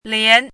lián
拼音： lián
注音： ㄌㄧㄢˊ
lian2.mp3